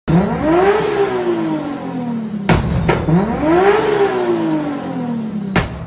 RB26 with pops & bangs? sound effects free download